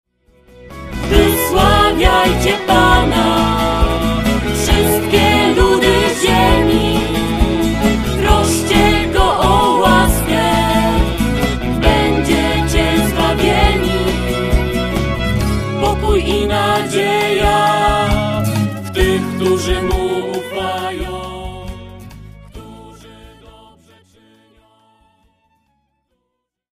Posłuchaj nagrań wersji wokalnych utworów zespołu